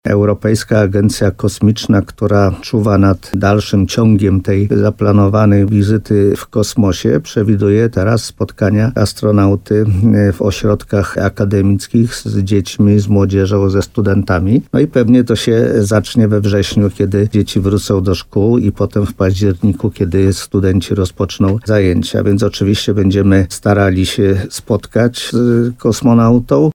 mówił w programie Słowo za słowo na antenie RDN Nowy Sącz